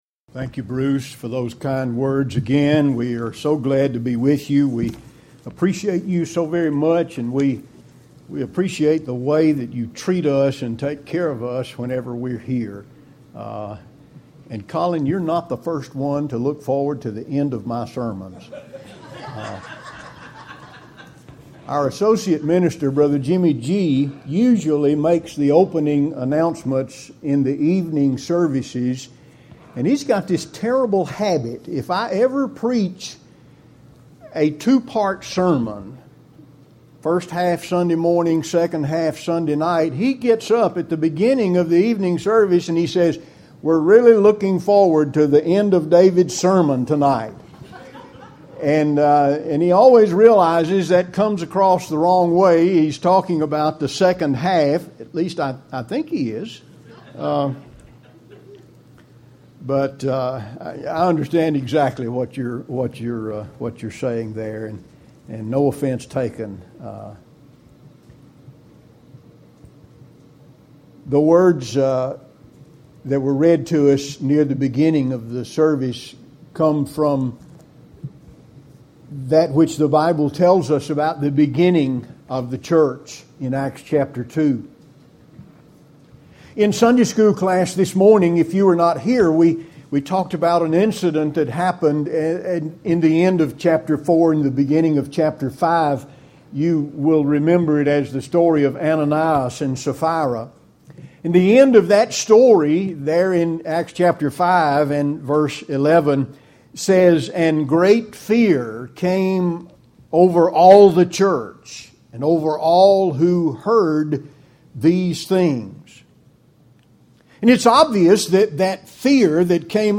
2019 (AM Worship - Gospel Meeting) "Rethinking The Church"